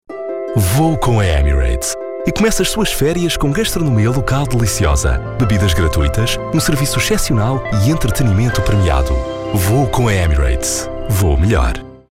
I have a deep low male tone, calm and smooth, usually chosen for corporate, elearning, explainers, narration and commercials.
Radio Commercials
Radio Commerial
Words that describe my voice are Portuguese, deep, low tone.